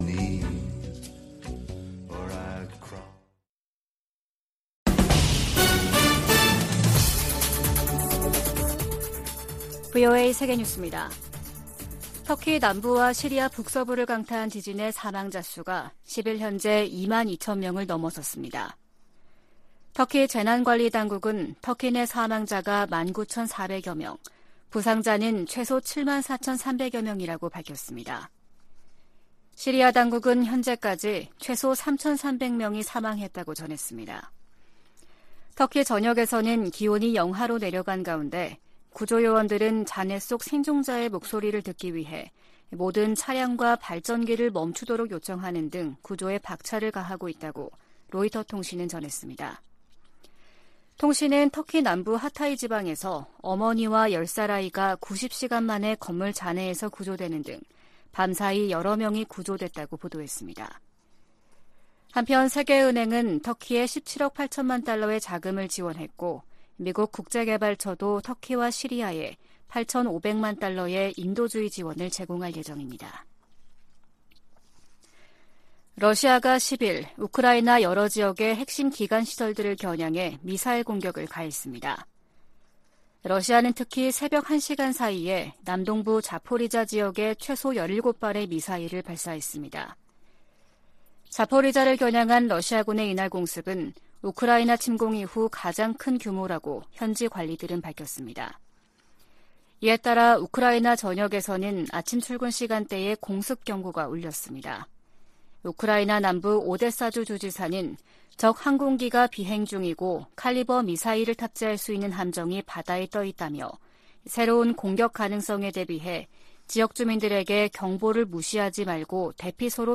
VOA 한국어 아침 뉴스 프로그램 '워싱턴 뉴스 광장' 2023년 2월 11일 방송입니다. 한국 정부가 사이버 분야에 첫 대북 독자 제재를 단행했습니다. 미국 국무부는 북한이 건군절 열병식을 개최하며 다양한 무기를 공개한 상황에서도 한반도 비핵화 목표에 변함이 없다고 밝혔습니다.